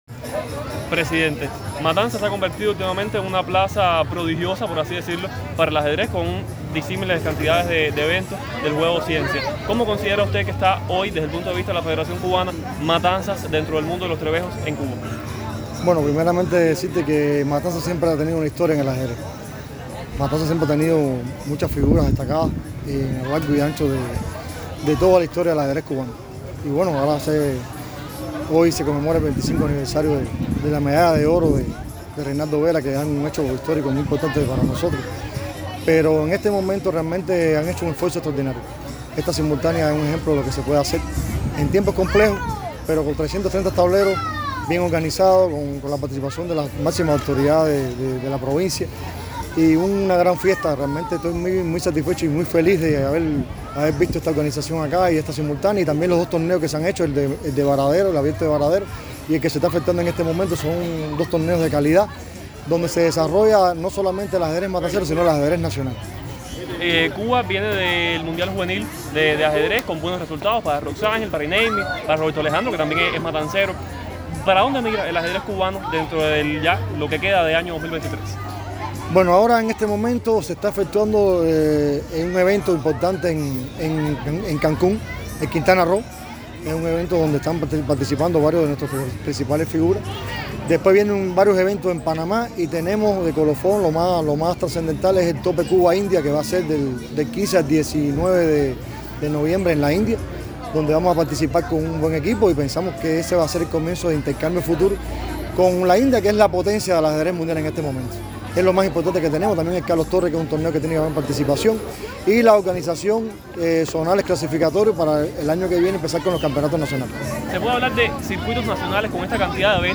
durante un apartado con la prensa en medio de los festejos del deporte matancero por los 330 años de la ciudad yumurina.